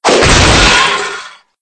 ENC_cogfall_apart_4.ogg